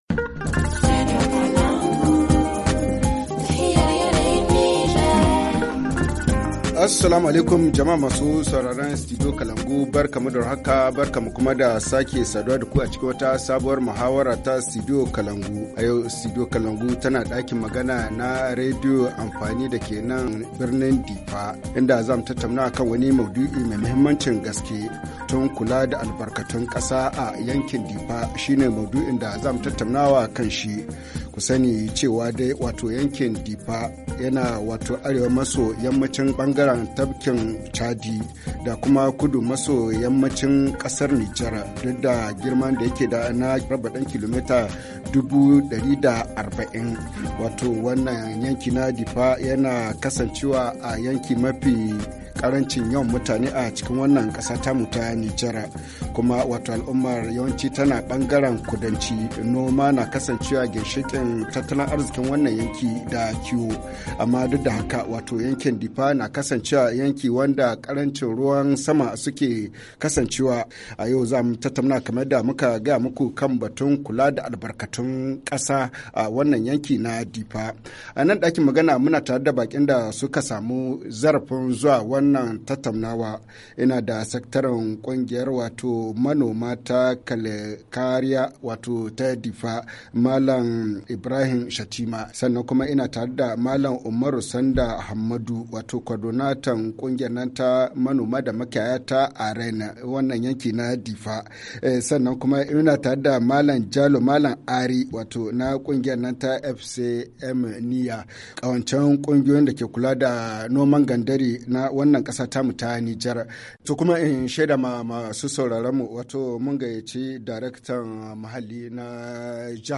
dans le studio de la radio Alternative de Diffa
Le forum en haoussa